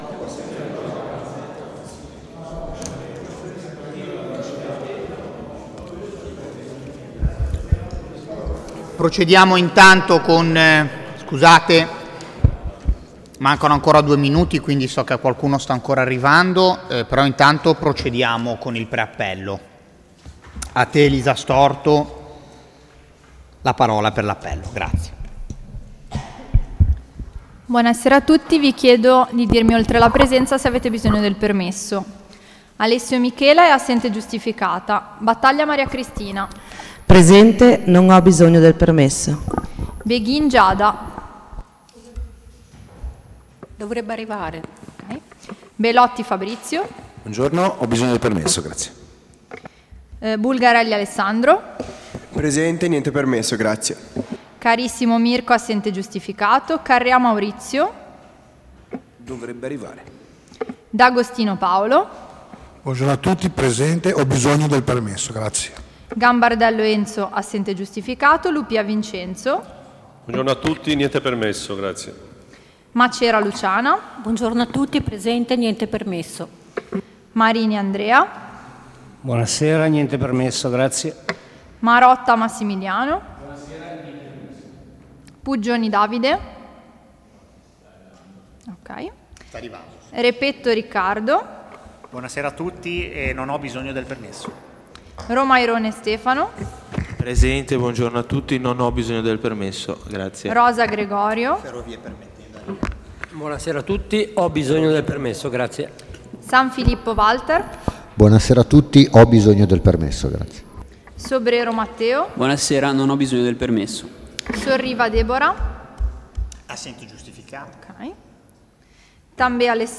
Luogo: Sala Consiliare in Via Guido Poli 12
Audio seduta